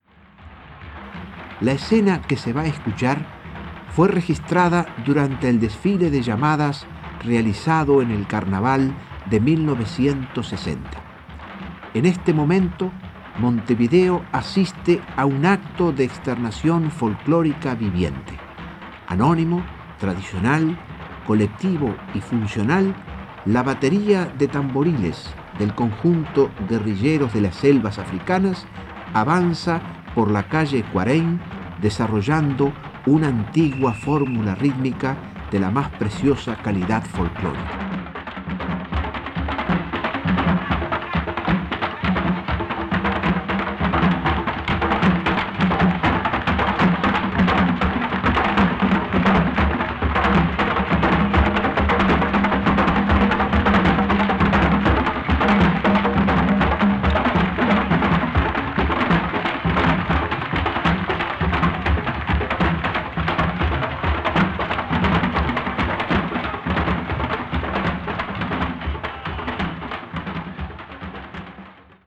Locución: Lauro Ayestarán
Grabación de campo emitida en la audición y utilizada en este micro radial:
Especie: candombe
Ejecutantes e instrumental: “Guerreros de las selvas africanas”, tamboriles
Localidad: Calle Cuareim (hoy Zelmar Michelini), departamento de Montevideo, Uruguay